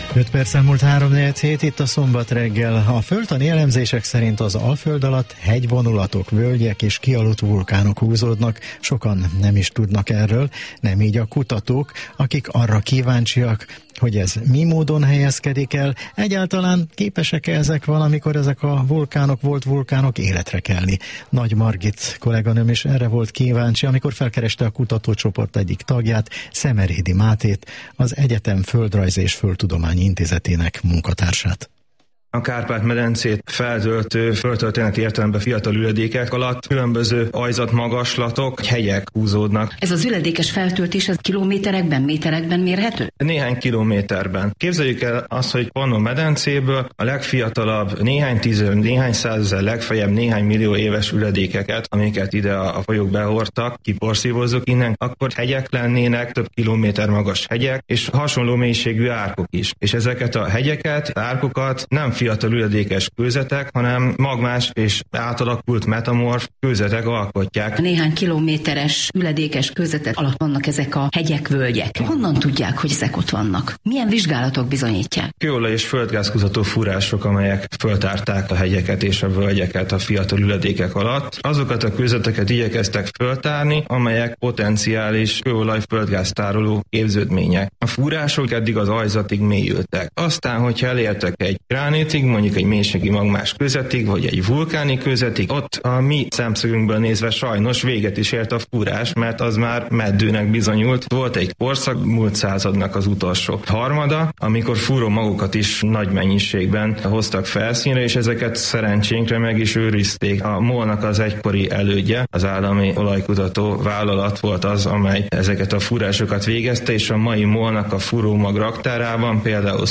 Az SZTE "Vulcano" kutatócsoport kuatásairól volt szó a Kossuth Rádióban
Az interjúban elhangzott eredmények a Nemzeti Kutatási, Fejlesztési és Innovációs Hivatal támogatásával (pályázat azonosító: K131690) valósultak meg.